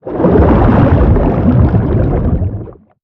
Sfx_creature_shadowleviathan_swimpatrol_05.ogg